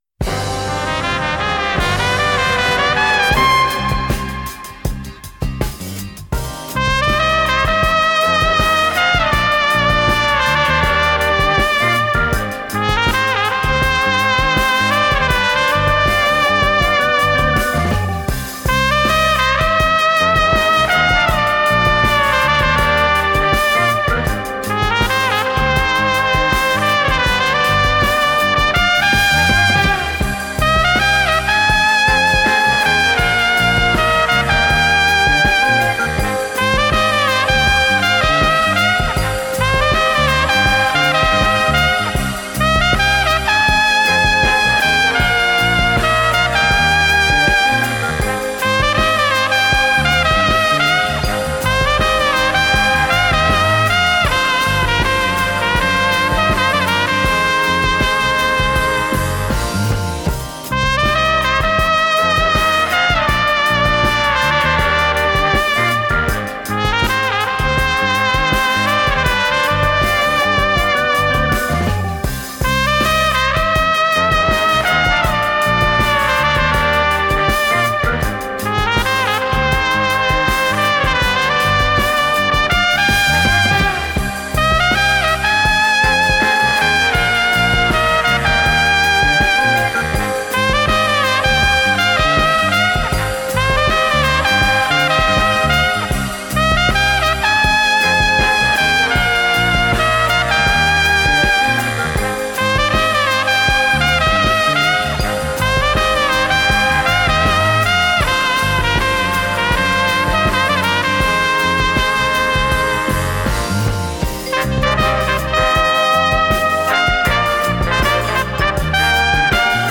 Французский трубач, руководитель оркестра.